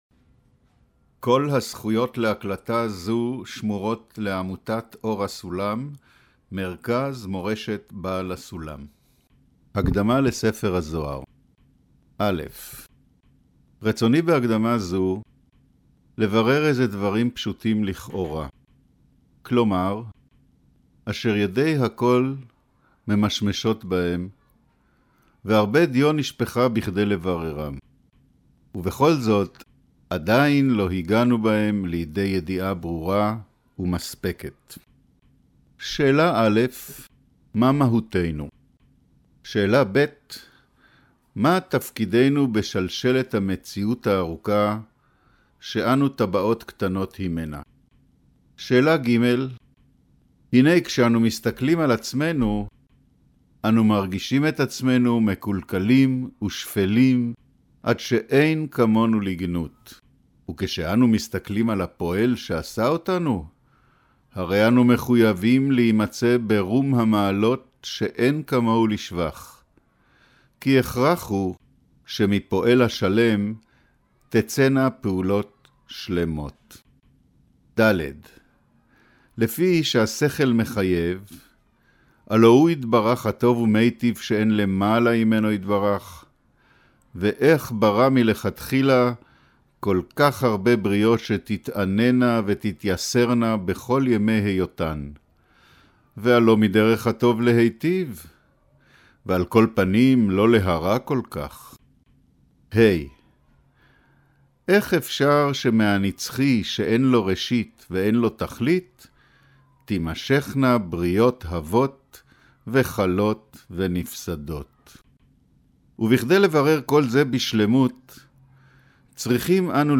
אודיו - קריינות הקדמה לספר הזהר